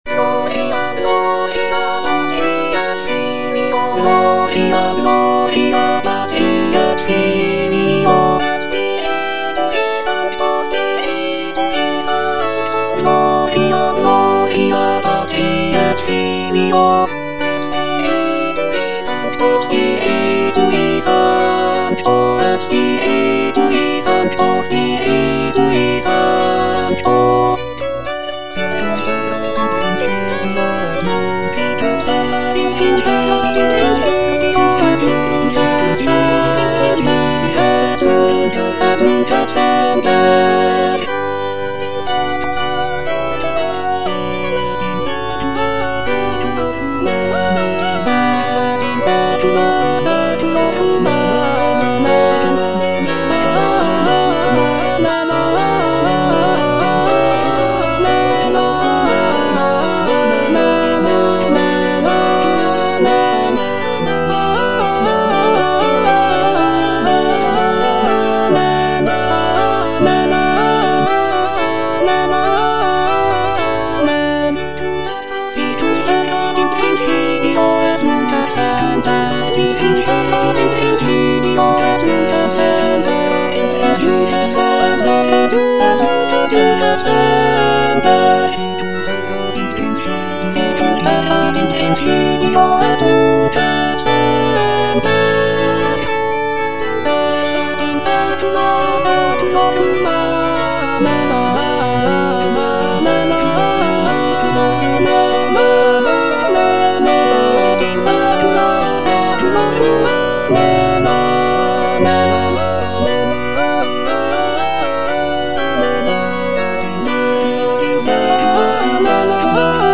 M.R. DE LALANDE - CONFITEBOR TIBI DOMINE Gloria Patri (grand choeur) - Alto (Emphasised voice and other voices) Ads stop: auto-stop
Lalande's composition features intricate polyphony, lush harmonies, and expressive melodies, reflecting the Baroque style of the period.